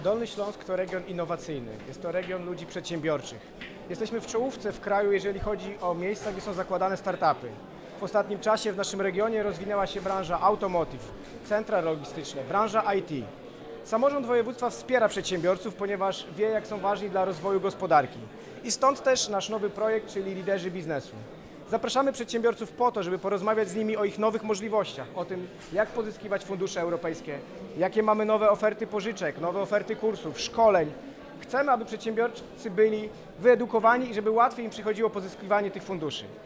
Jak zaznacza wicemarszałek województwa dolnośląskiego Michał Rado, Dolny Śląsk przoduje w Polsce jeśli chodzi o rozwój start – upów. Przy okazji zaprasza pozostałych przedsiębiorców do uczestnictwa w projekcie.